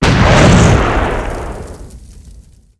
1 channel
Napalmexplosion2.wav